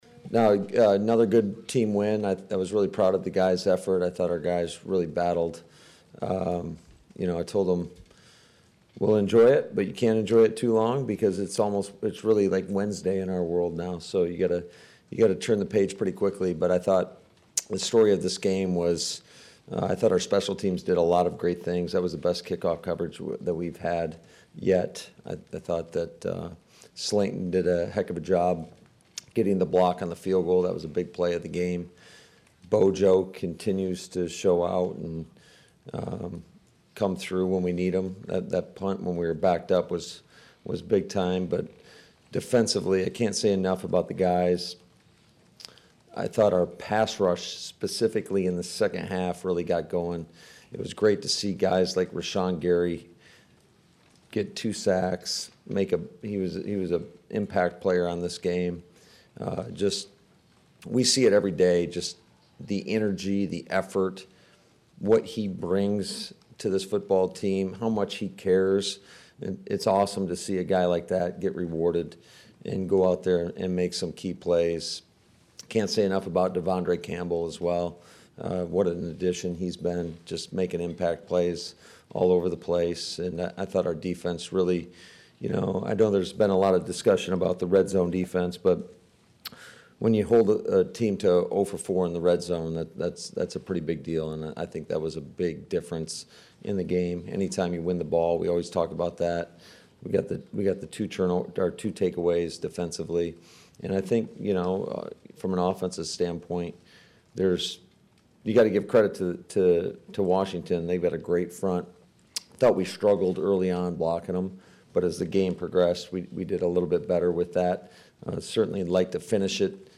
Matt LaFleur covered all three phases to lead off his post-game press conference.